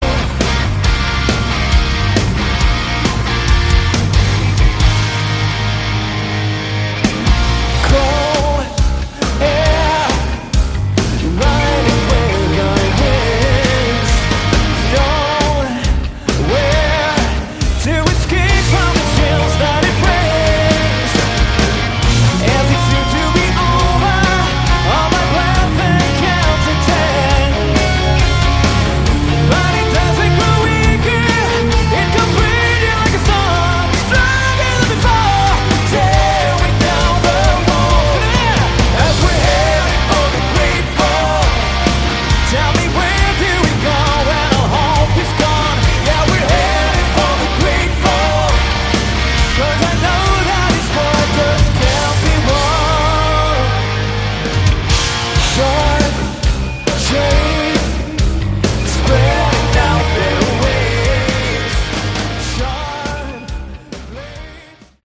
Category: Melodic Rock
lead vocals, keyboards
rhythm and lead guitars, backing and lead vocals
drums
bass